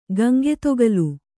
♪ Gaŋgetogalu